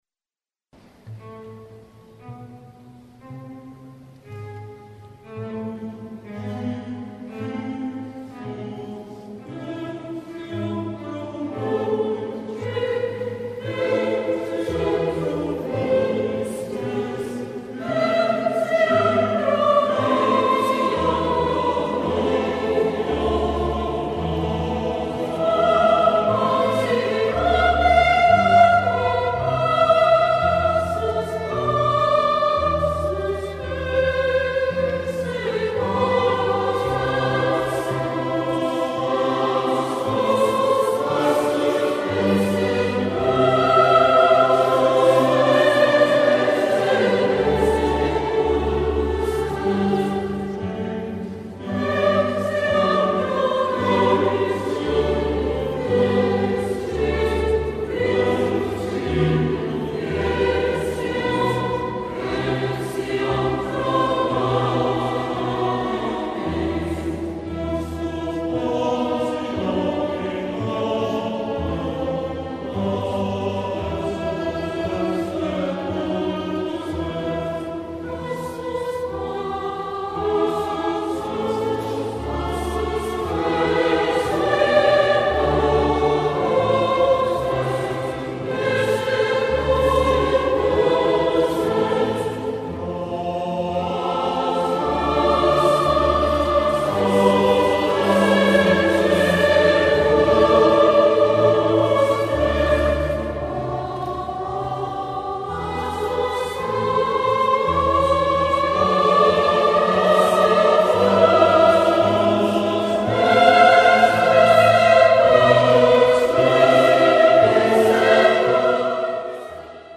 IL CORO dell'AVENTINO di Roma: un coro importante, bellissimo.
Il Coro, composto da circa 70 elementi, si è esibito in numerosi concerti presso le più prestigiose basiliche di Roma ed anche a favore di iniziative di solidarietà.
Crucifixus (Vivaldi) (Coro  Aventino).mp3